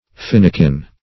Search Result for " finnikin" : The Collaborative International Dictionary of English v.0.48: Finnikin \Fin"ni*kin\, n. (Zool.) A variety of pigeon, with a crest somewhat resembling the mane of a horse.